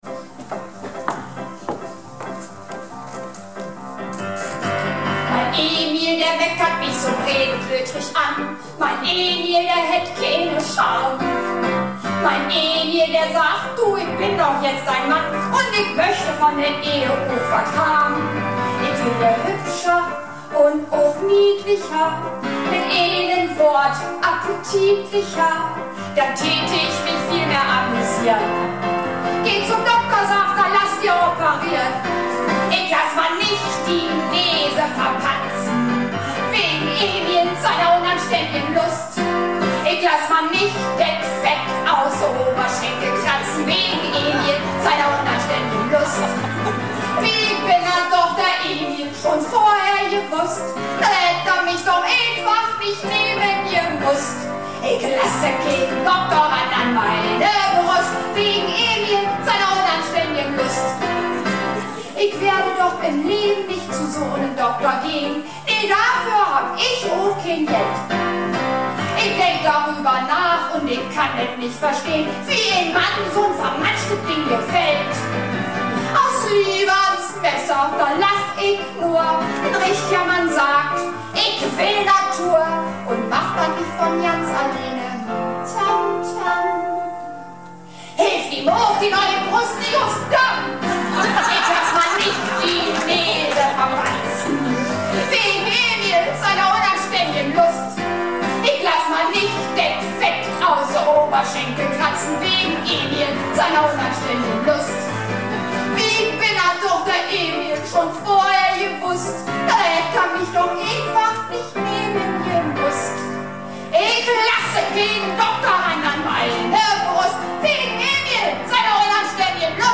Theater - "Du musst es wagen - Sünner Tegenstöten word nümms deftig" am 18.03.2012 in Emden